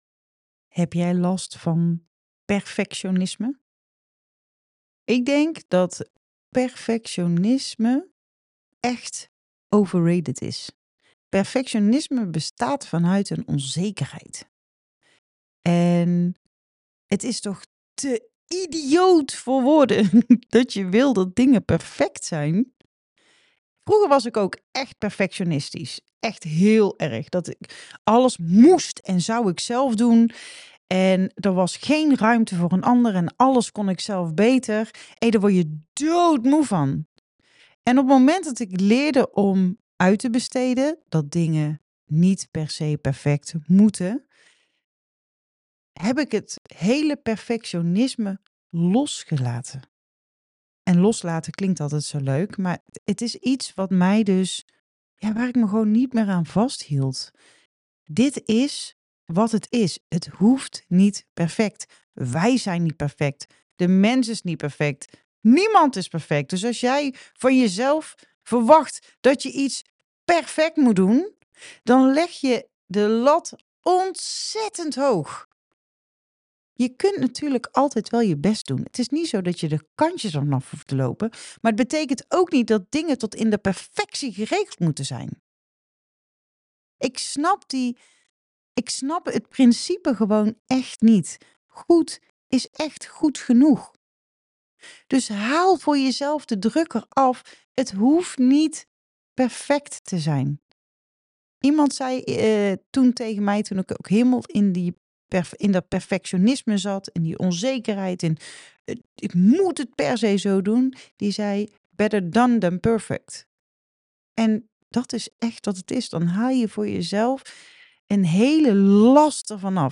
Hoe kun je perfectionisme stap voor stap loslaten? Daar vertel ik je meer over in mijn voicebericht.